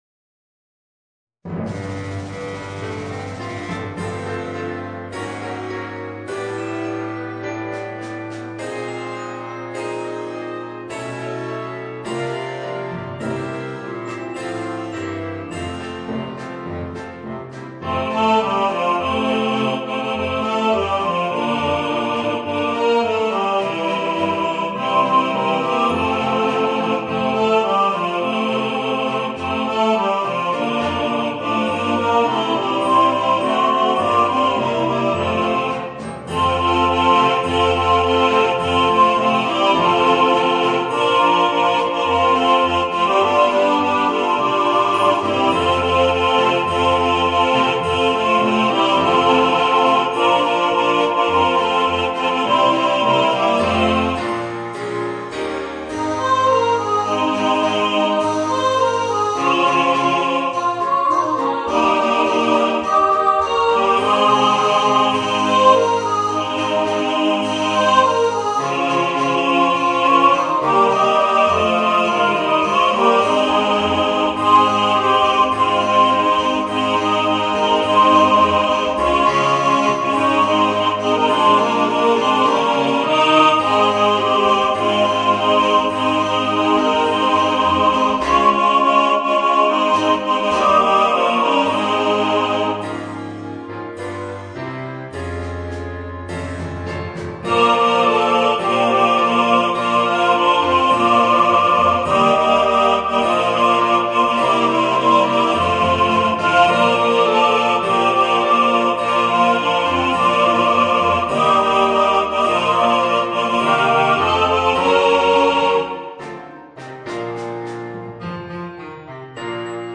Voicing: Chorus